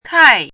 “炌”读音
kài
炌字注音：ㄎㄞˋ
国际音标：kʰĄi˥˧
kài.mp3